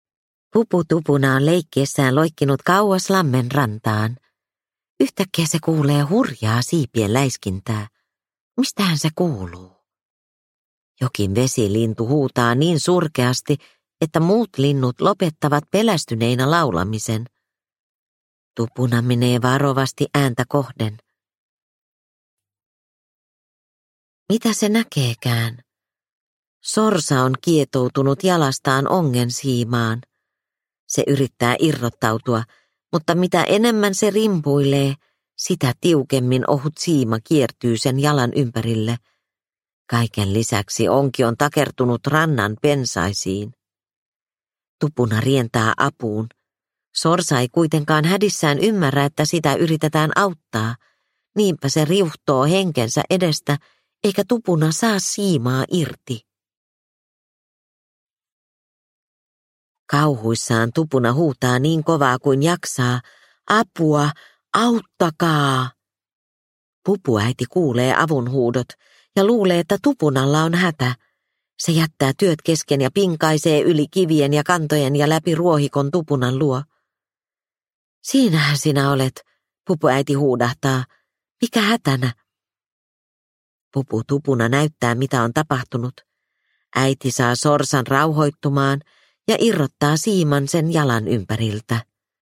Avulias Pupu Tupuna – Ljudbok – Laddas ner